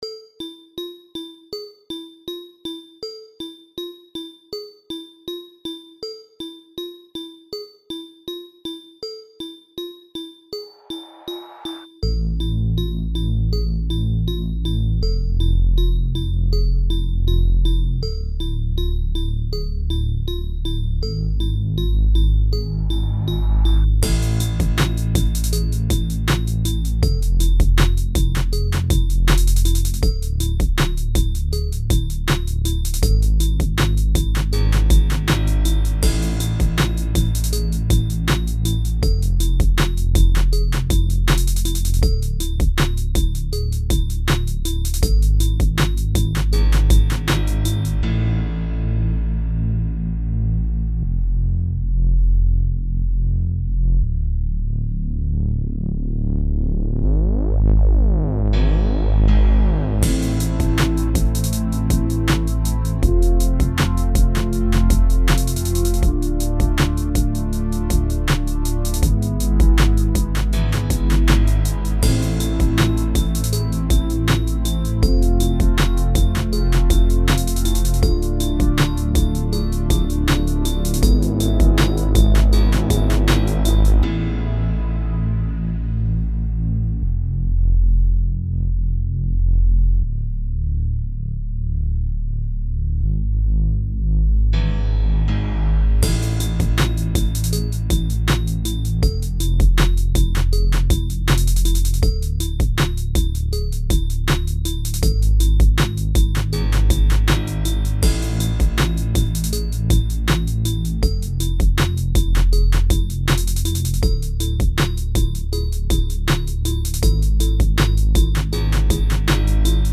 Und so funktioniert´s Der Rap hat immer abwechselnd einen ruhigeren Teil und dann einen rhythmischen Teil.